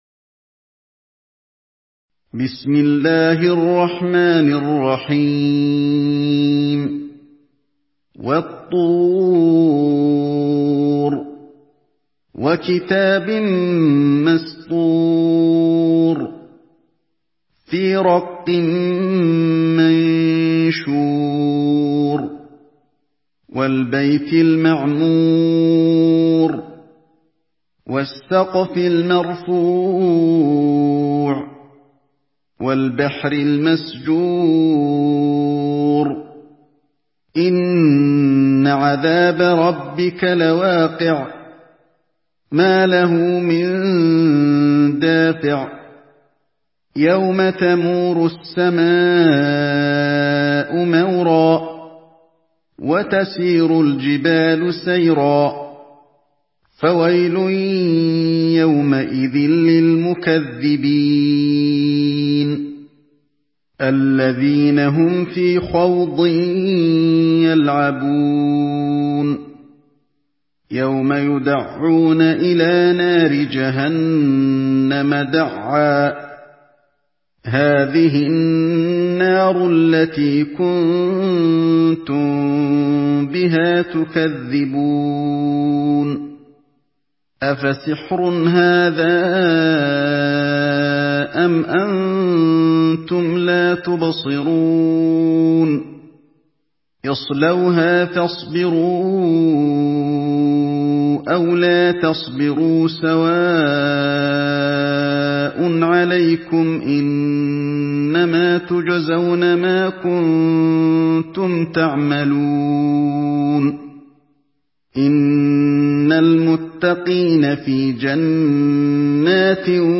سورة الطور MP3 بصوت علي الحذيفي برواية حفص
مرتل